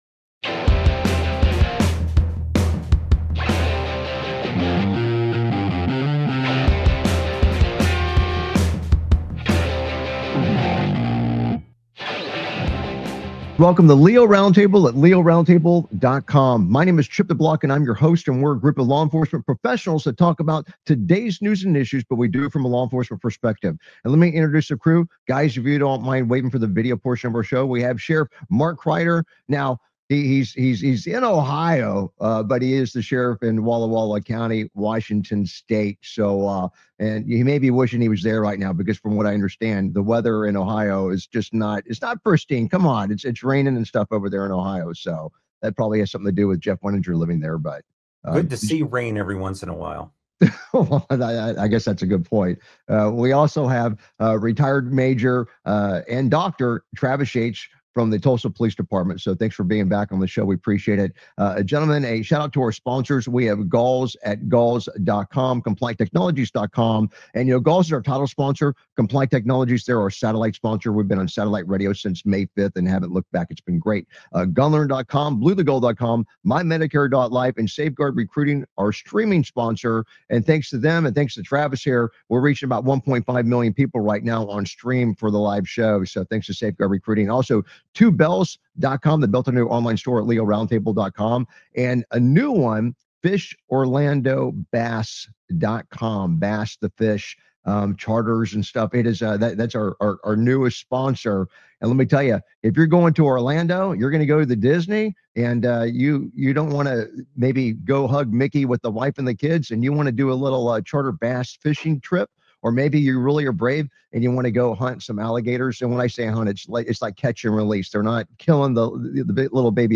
Talk Show Episode, Audio Podcast, LEO Round Table and S10E153, Bad Guy Hops In Cops Cruiser And Tries To Take The Officer With Him on , show guests , about Bad Guy Hops In Cops Cruiser,Tries To Take The Officer With Him, categorized as Entertainment,Military,News,Politics & Government,National,World,Society and Culture,Technology,Theory & Conspiracy